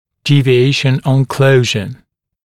[ˌdiːvɪ’eɪʃ(ə)n ən ‘kləuʒə][ˌди:ви’эйш(э)н он ‘клоужэ]отклонение (нижней челюсти) при закрывании